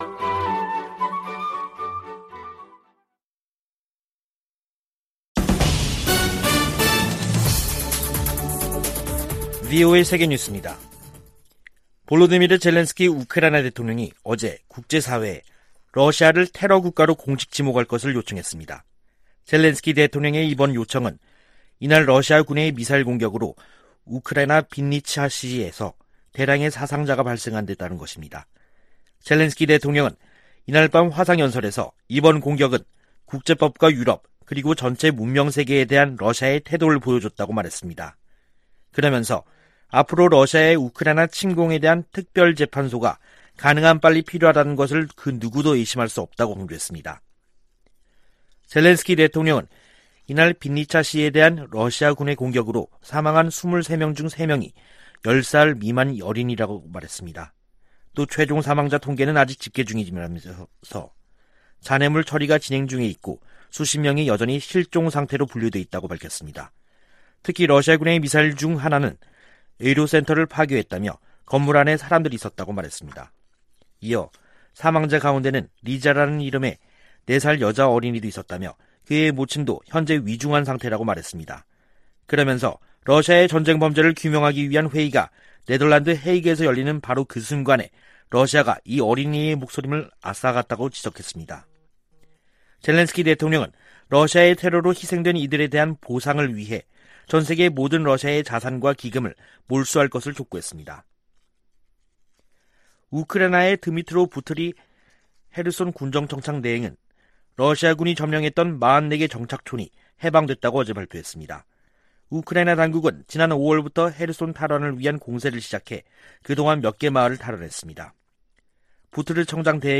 VOA 한국어 간판 뉴스 프로그램 '뉴스 투데이', 2022년 7월 15일 2부 방송입니다. 재닛 옐런 미 재무장관은 오는 19일 방한에서 경제관계를 강화하고 대북 제재를 이행 문제 등을 논의할 것이라고 밝혔습니다. 미한 공군의 F-35A 스텔스 연합비행훈련은 북한 도발에 전략자산으로 대응할 것이라는 경고를 보내는 것이라고 미국 군사전문가들은 평가했습니다. 마크 에스퍼 전 미 국방장관은 '쿼드' 확대의 필요성을 강조하며 적합한 후보국으로 한국을 꼽았습니다.